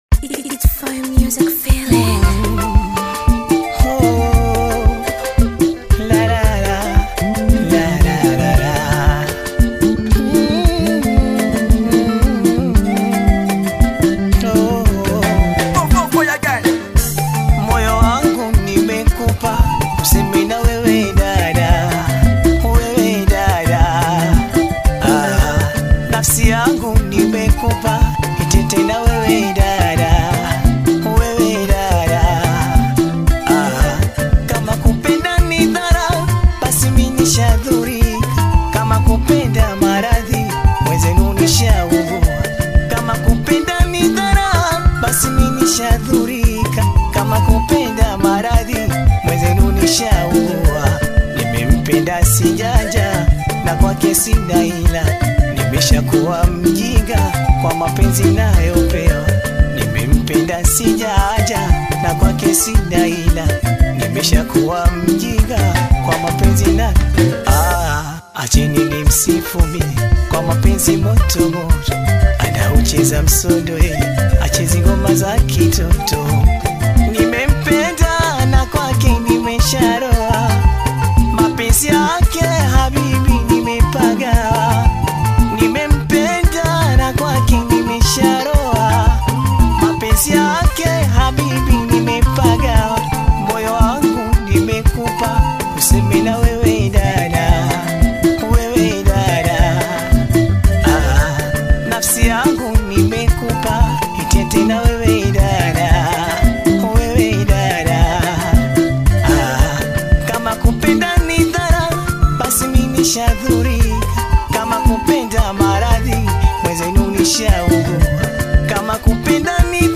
BAIKOKO TANGA